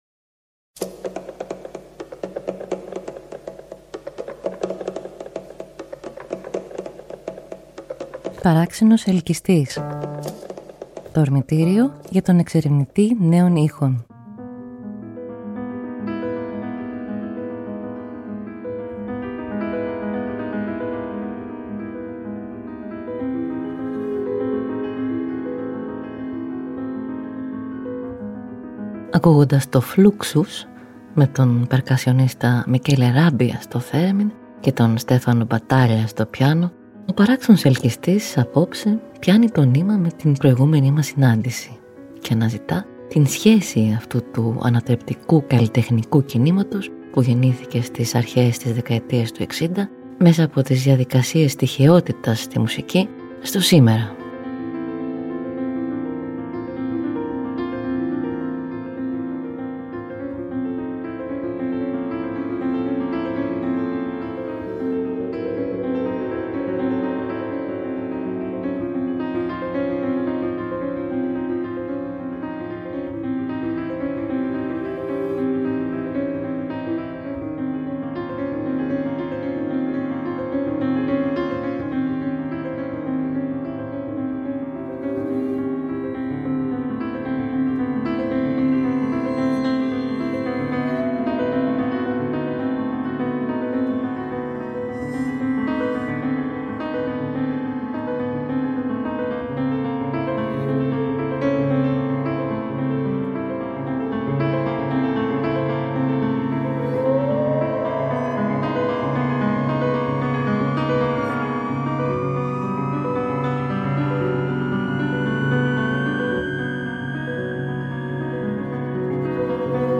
ένα avant-jazz fluxkit
” για σαξόφωνο, ντραμς και κουαρτέτο εγχόρδων.